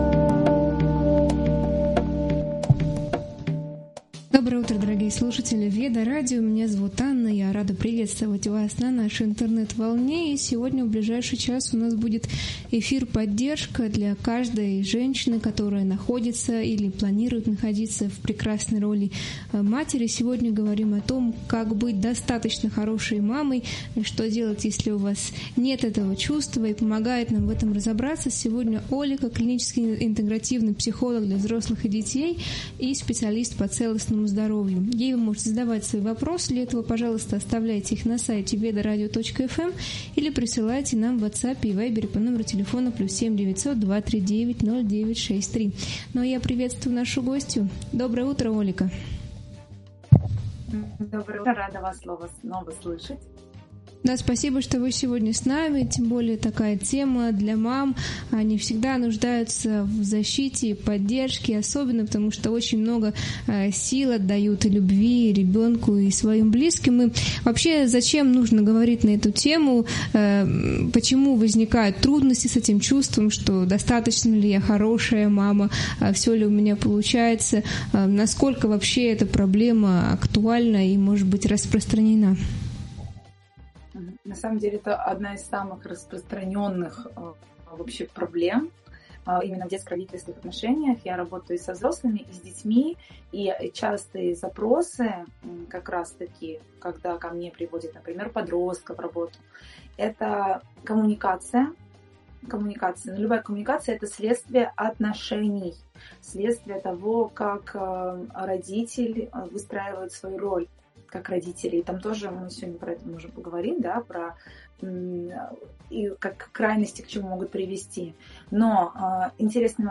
Эфир «Достаточно хорошая мама» раскрывает, как родителям сохранять эмоциональный баланс, поддерживать себя и ребёнка, не стремясь к идеалу. Обсуждаются стратегии воспитания, особенности детей с СДВГ, влияние питания и медиа, подходы к обучению, важность доверительных отношений с подростками, личностное развитие родителей, а также роль отдыха и заботы о ментальном здоровье семьи.